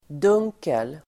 Uttal: [d'ung:kel]